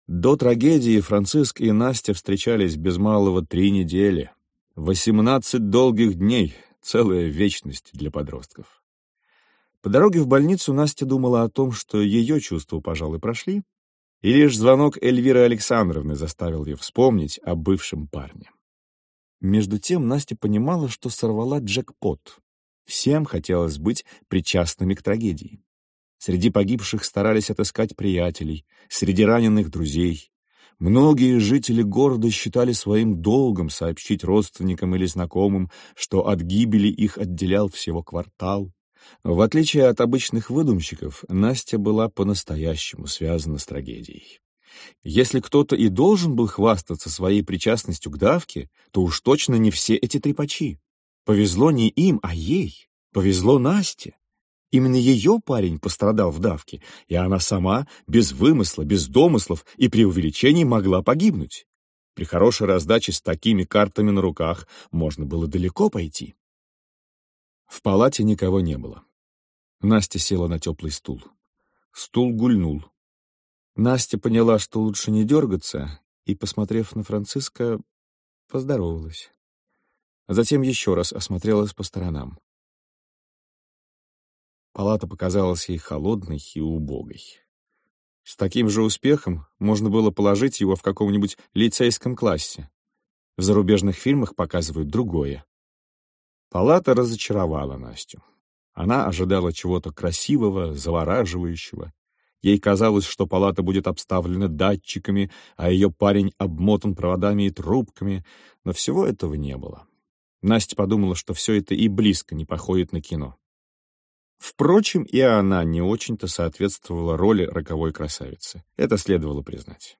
Aудиокнига Бывший сын Автор Саша Филипенко Читает аудиокнигу Анатолий Белый.